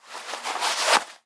Звук снятой кожаной перчатки с руки